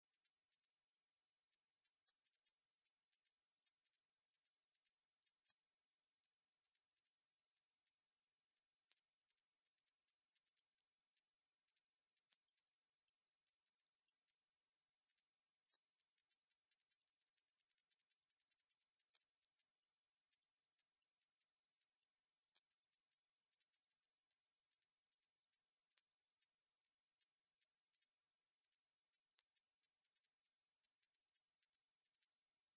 Эти аудиозаписи содержат высокочастотные сигналы и другие звуковые эффекты, неприятные для насекомых.
Звук для отпугивания тараканов